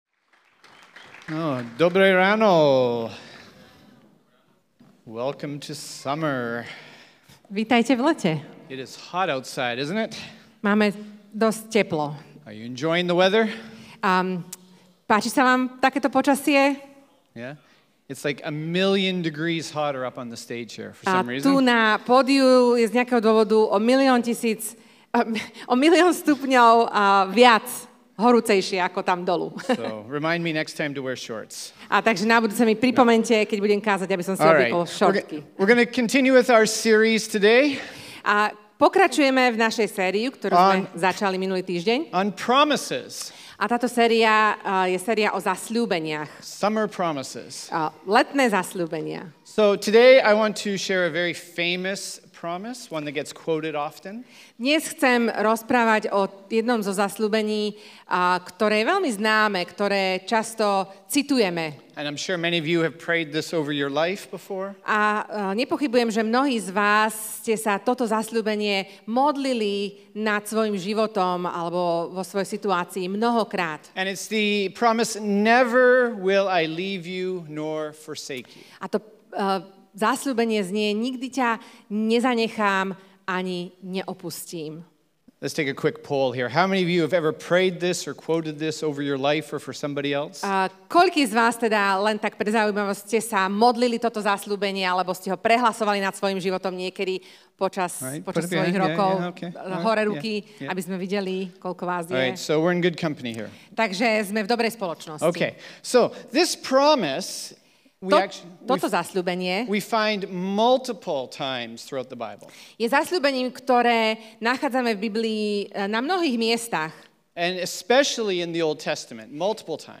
Nikdy ťa neopustím Kázeň týždňa Zo série kázní